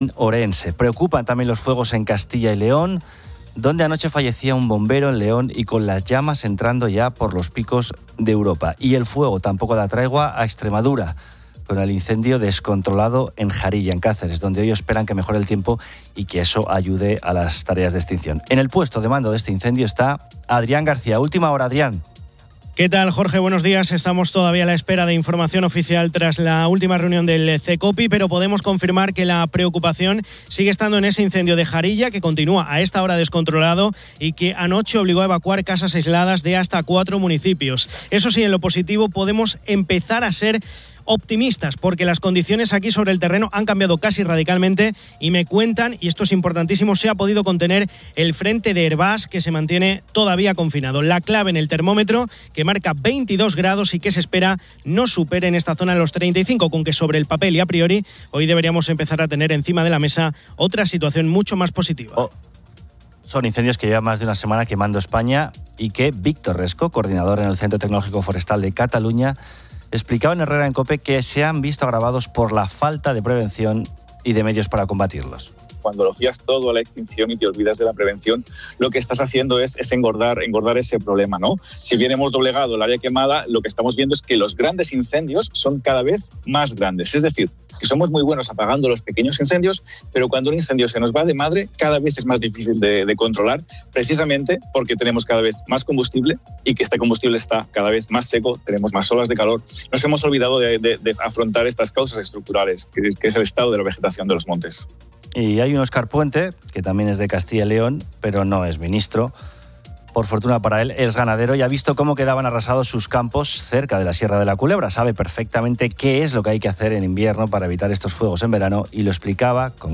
Se critica la falta de prevención y medios, y un ganadero de la Sierra de la Culebra subraya la importancia del cuidado del monte en invierno. También se informa sobre la manifestación en Israel por los rehenes y los planes de Netanyahu sobre Gaza y Cisjordania, así como la hambruna en Gaza.